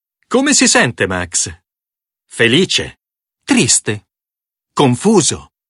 dog
happy